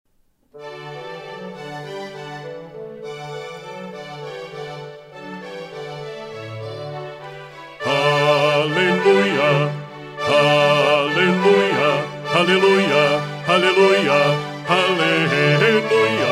Estão disponíveis arquivos com a partitura e gravações das vozes para estudo:
Gravação Baixo
baixo.mp3